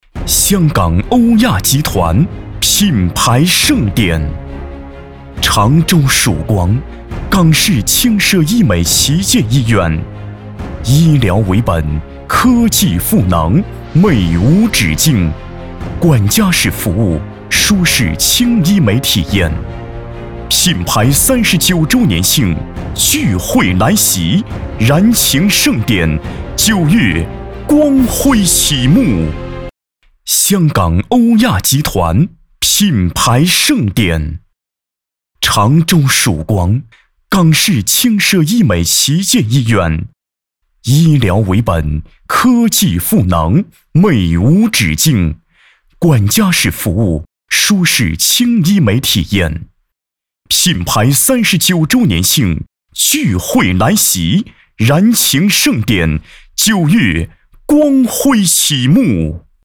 男30号配音师
声音年轻大气，时尚科技，积极年轻。